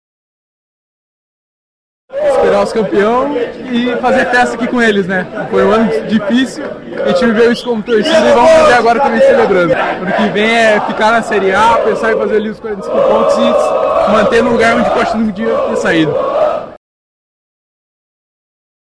A chegada da equipe transformou o saguão em um corredor verde e branco, com cantos, bandeiras e muita comemoração pela conquista da Série B do Campeonato Brasileiro.